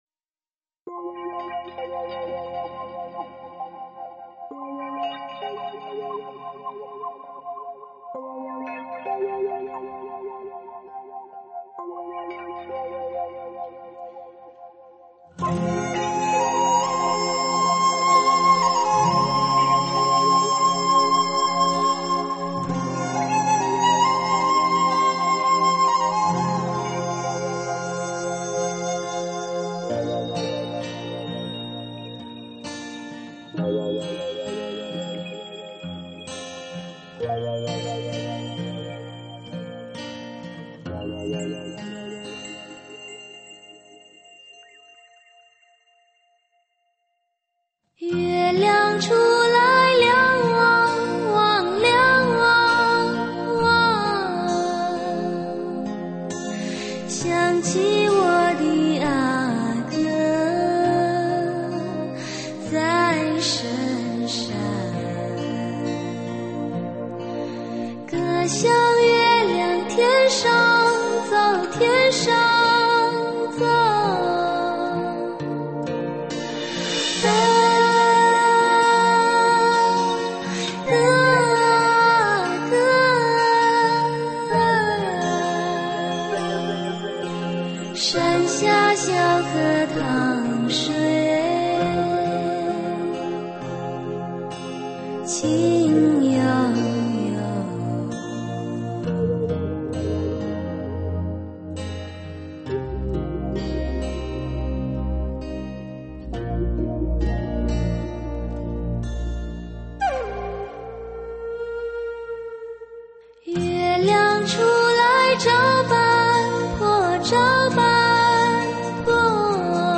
[14/7/2008]云南民歌--《小河淌水》